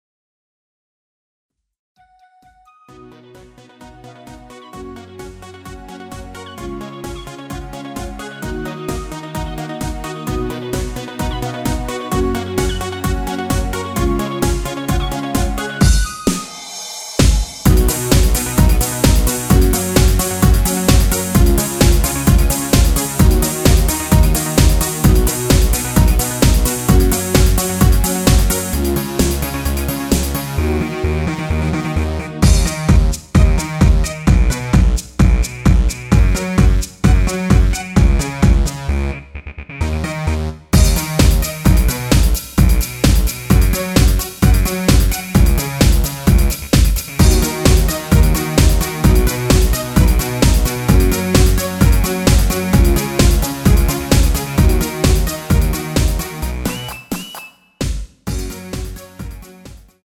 Ebm
앞부분30초, 뒷부분30초씩 편집해서 올려 드리고 있습니다.
중간에 음이 끈어지고 다시 나오는 이유는